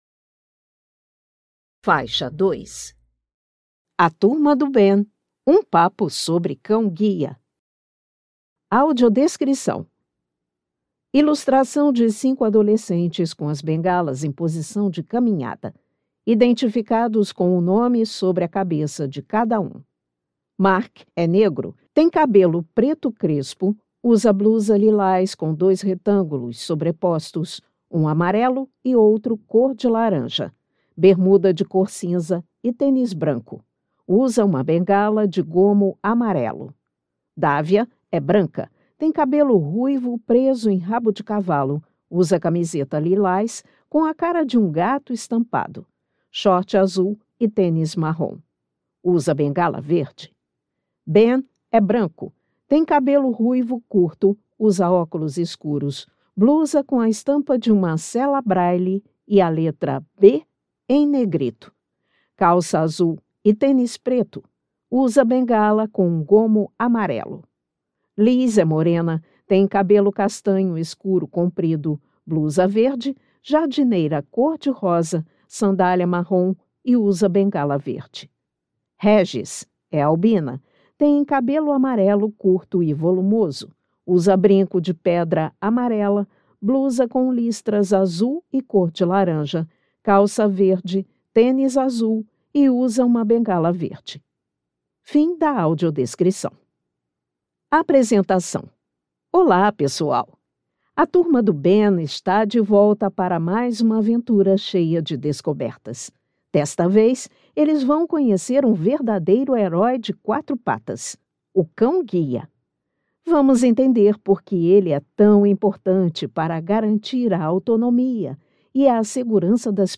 Audiolivro Um papo sobre cão-guia — IBC